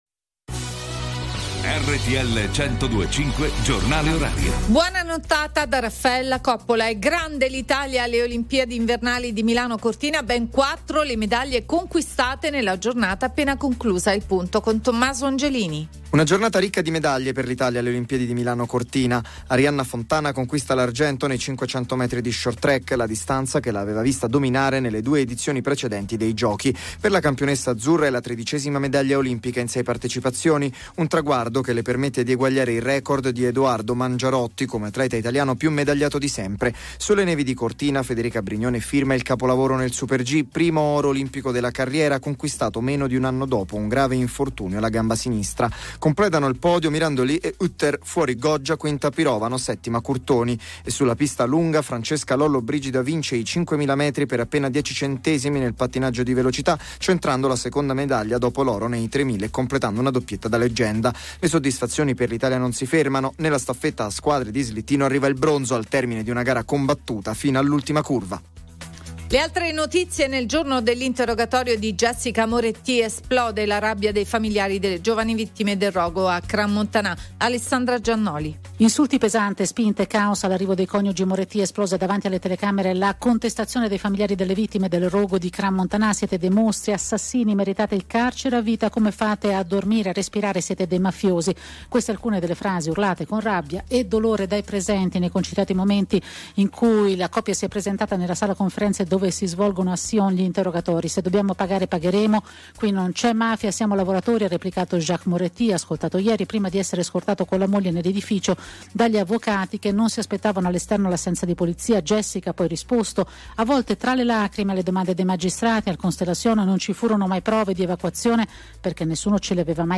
RTL 102.5 Hourly News